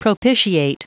Prononciation du mot propitiate en anglais (fichier audio)
Prononciation du mot : propitiate